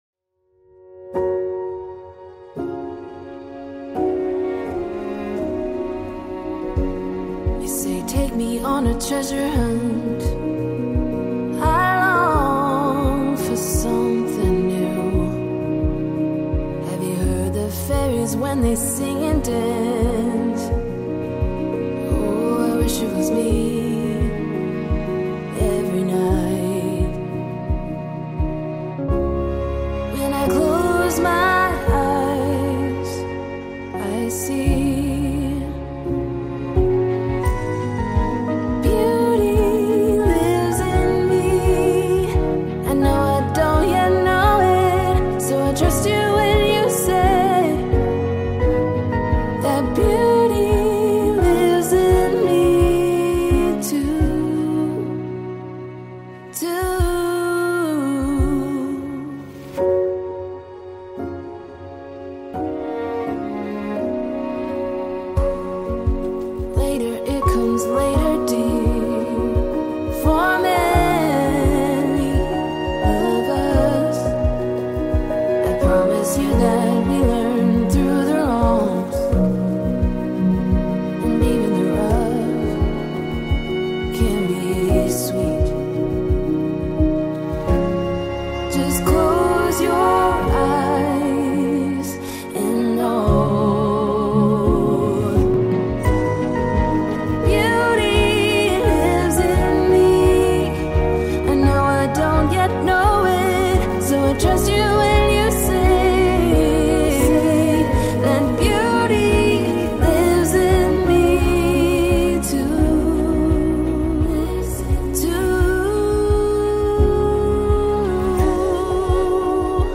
Minuteur 528 Hz : Focus Profond pour Objectifs